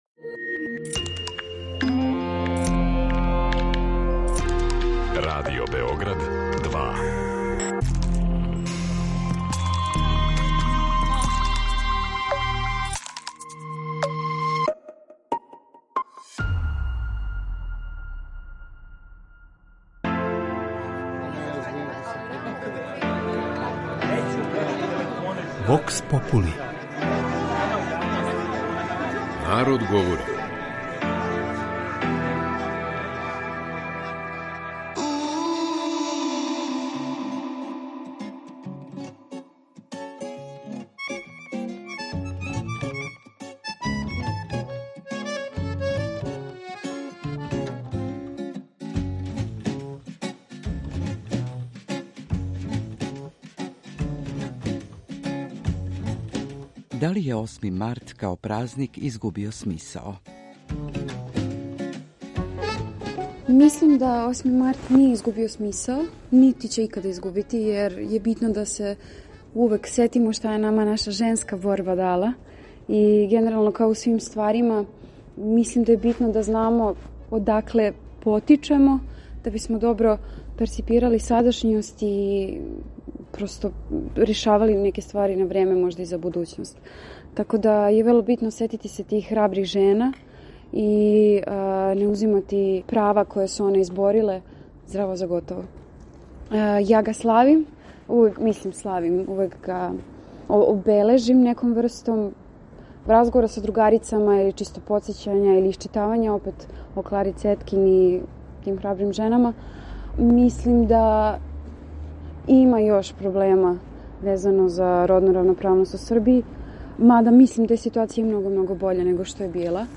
У сусрет осмом марту разговарали смо с неколико пролазница о њиховом доживљају празника који слави жене и њихову борбу за људска права, очекивањима и промишљањима везаним за тај датум.